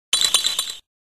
Play #582 Vanillite's Cry - SoundBoardGuy
pokemon-black-and-white-vanillites-cry.mp3